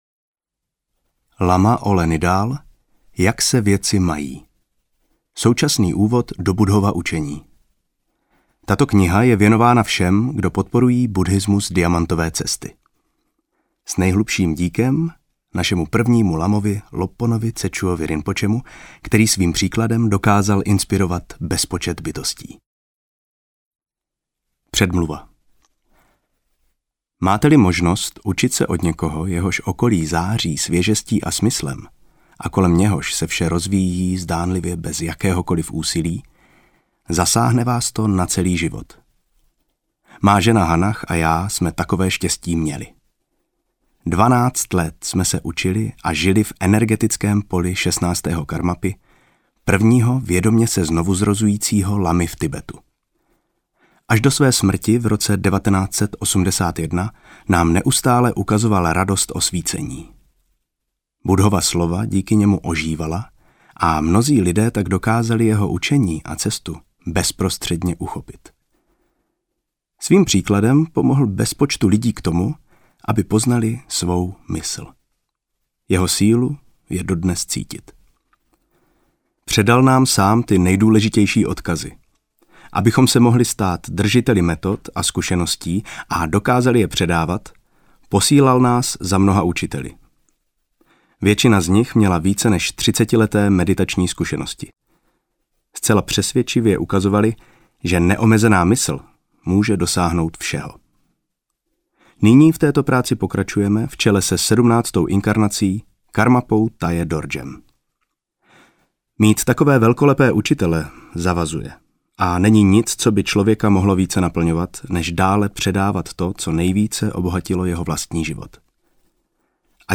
Jak se věci mají audiokniha
Ukázka z knihy